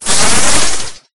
Wind6.ogg